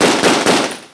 Semi-automatic & Fully-automatic
carbine.wav